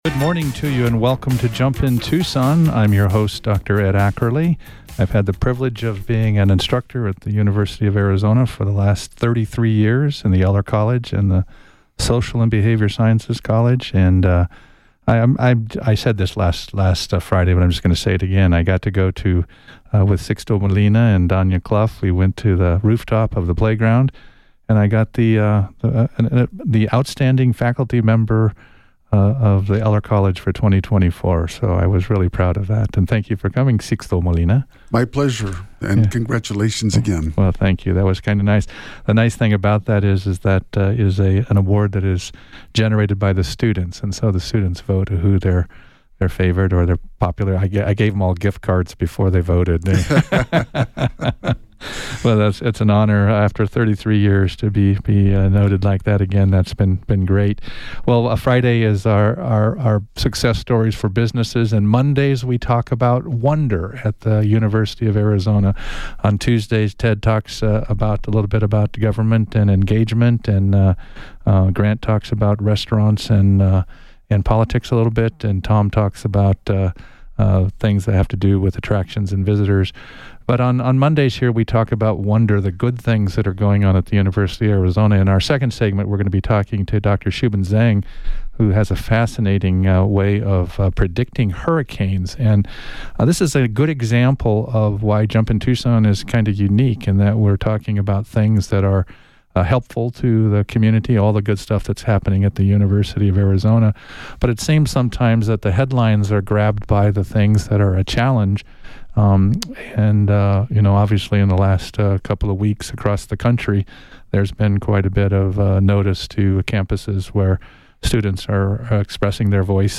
🎓 In this interview, a university professor 👨‍🔬 predicted that the 2024 hurricane season will be very active, with a lot of strong storms 🌀.